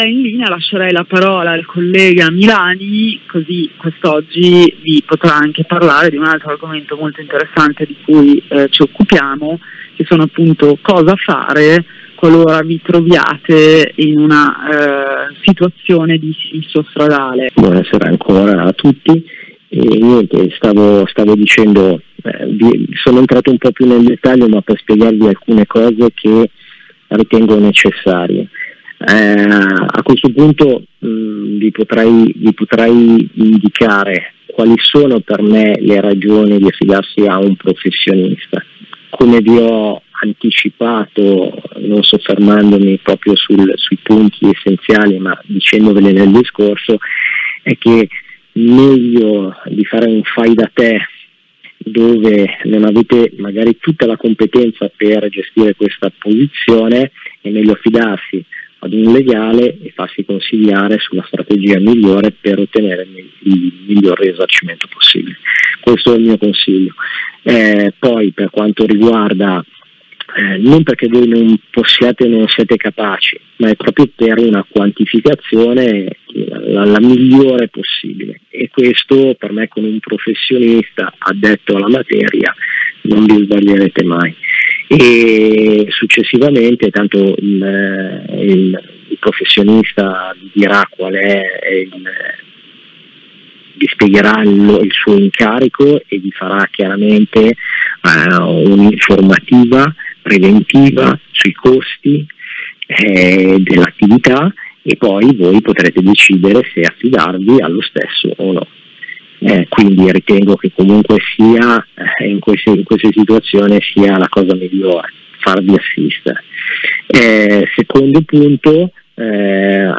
Diretta radio del 20/10/2021
Ascolta la trasmissione radio